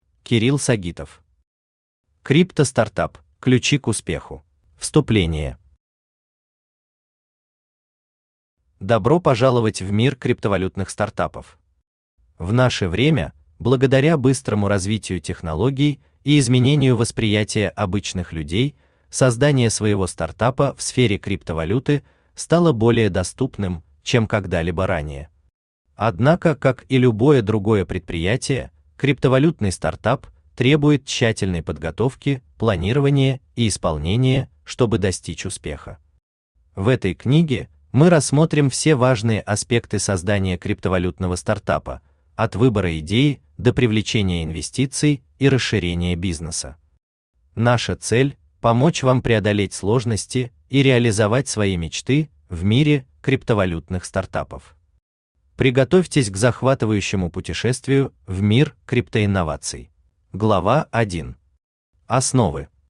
Аудиокнига Крипто-стартап: ключи к успеху | Библиотека аудиокниг
Aудиокнига Крипто-стартап: ключи к успеху Автор Кирилл Алексеевич Сагитов Читает аудиокнигу Авточтец ЛитРес.